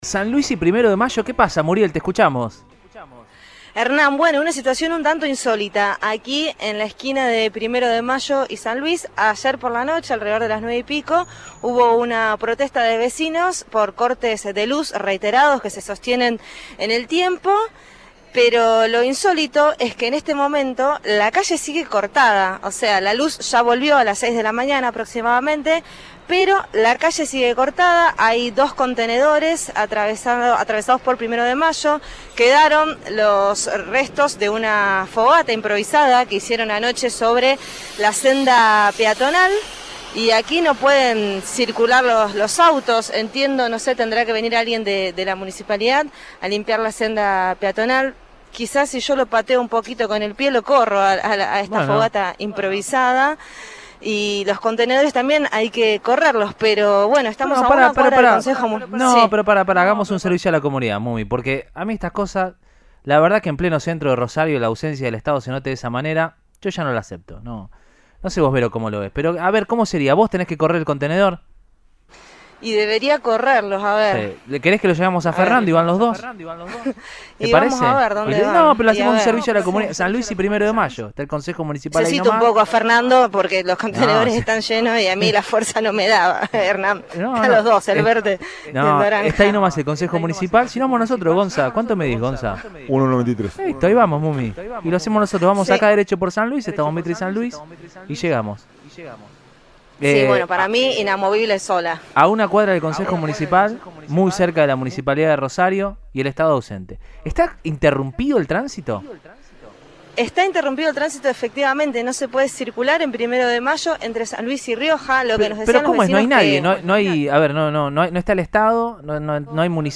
Sin embargo, el móvil de Cadena 3 Rosario, en Radioinforme 3, se acercó al lugar y el tránsito seguía cortado porque nadie había corrido los contenedores.